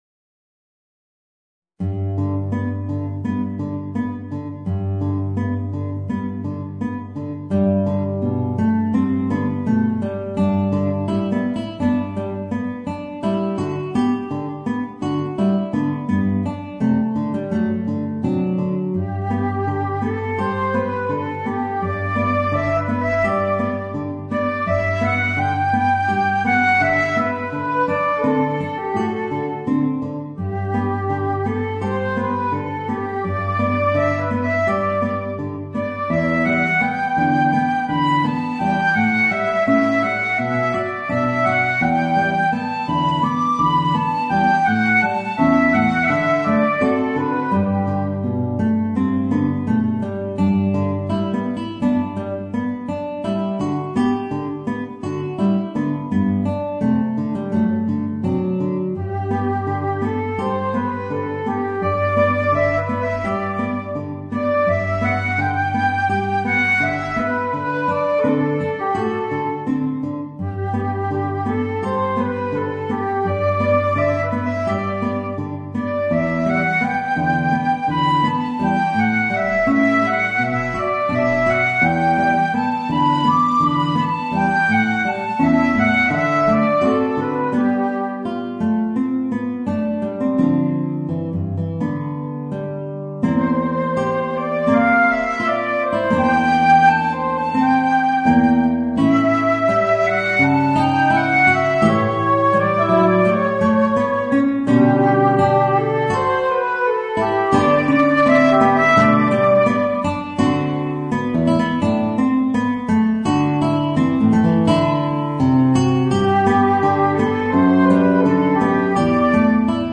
Voicing: Flute and Guitar